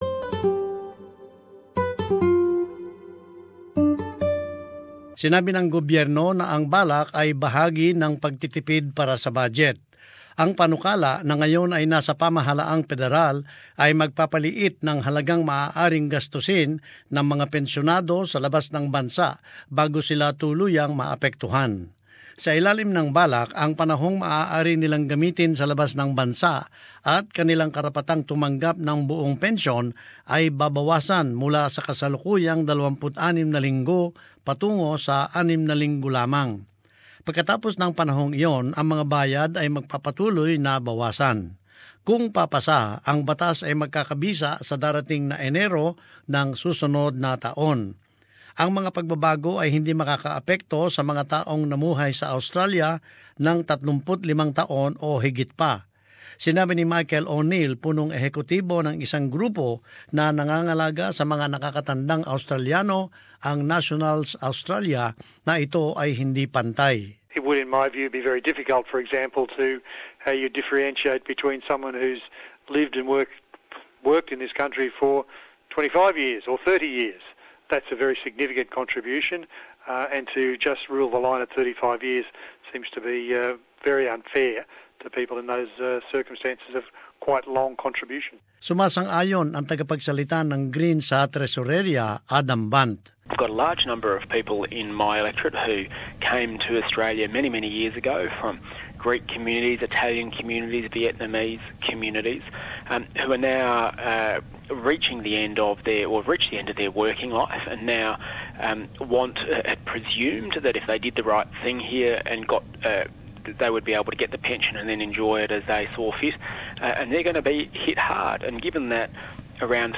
As this report shows, questions have been raised about whether some could receive any payments while overseas.